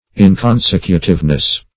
Search Result for " inconsecutiveness" : The Collaborative International Dictionary of English v.0.48: Inconsecutiveness \In`con*sec"u*tive*ness\, n. The state or quality of not being consecutive.